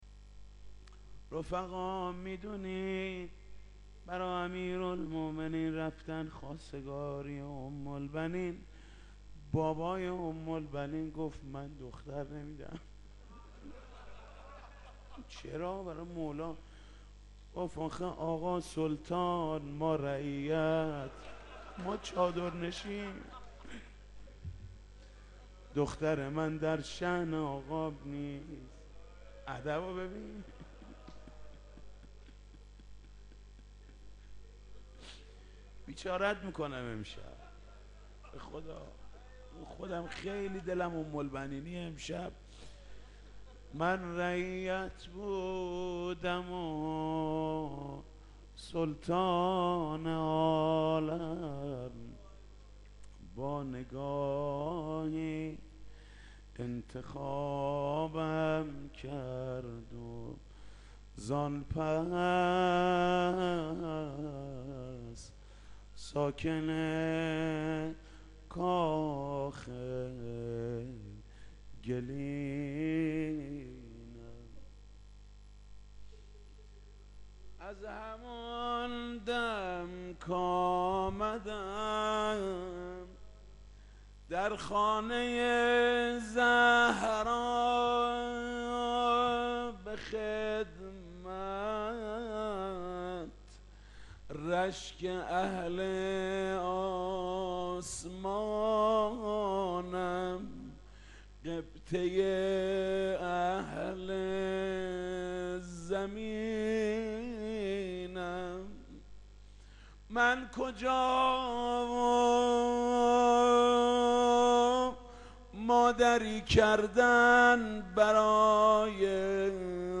حاج محود کریمی -روضه ام البنین- قسمت دوم-قزوین-آستان مقدس چهارانبیا-موسسه پرچمدار.mp3